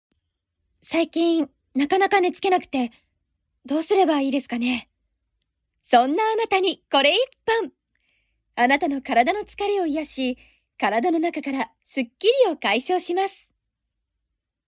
ボイスサンプル
CM２